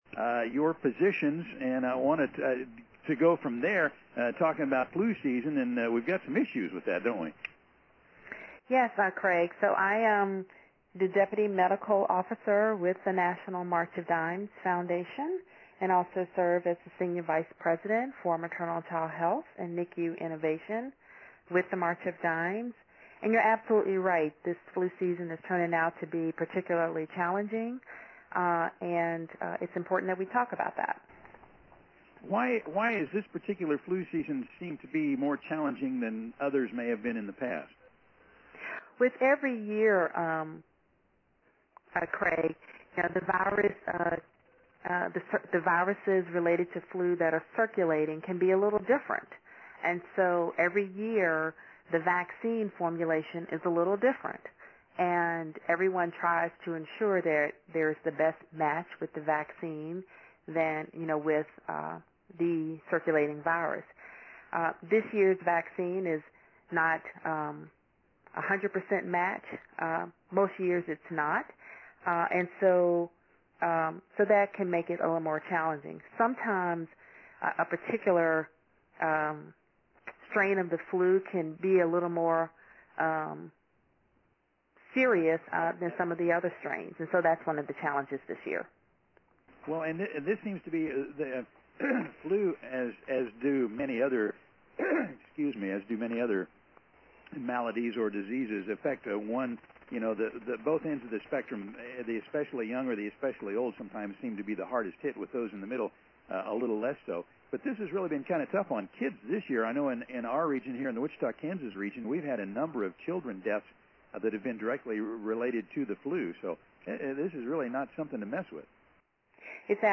Radio interviews:
10:03 am: five minute taped interview on Mid-Agriculture Radio Network; (stations in Nebraska, Kansas, Oklahoma and Texas).